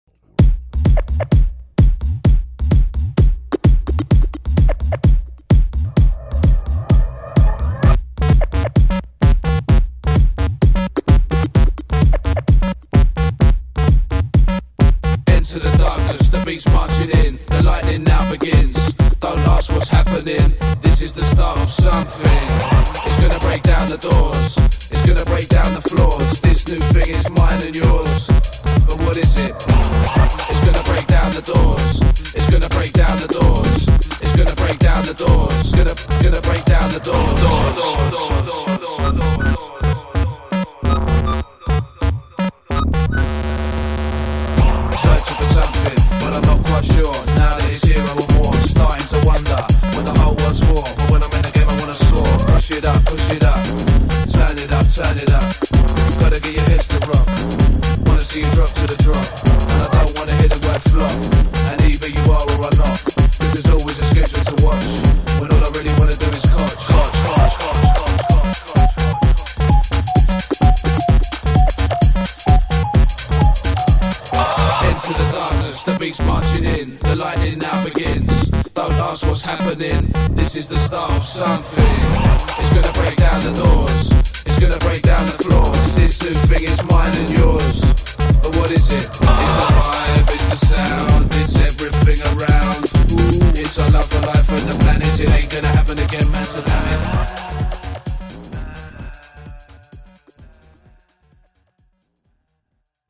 Жанр: Club • Dance • DJs